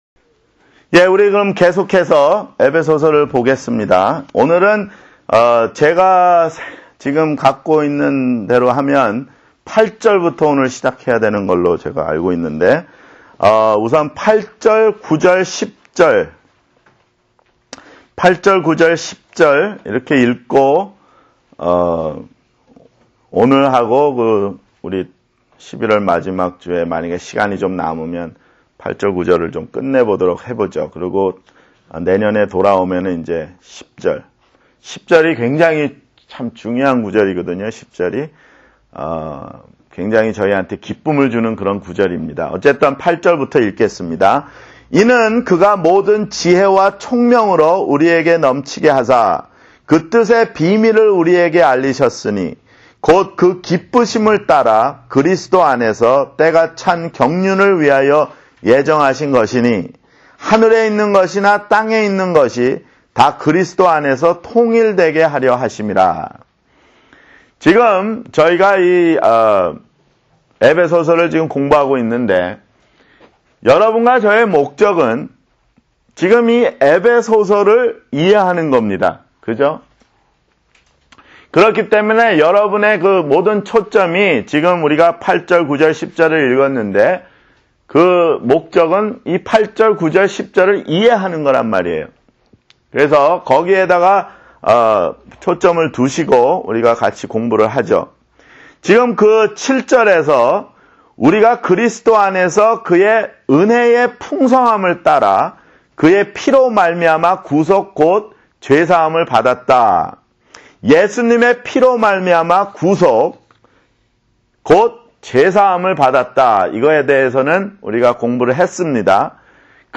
[성경공부] 에베소서 (13)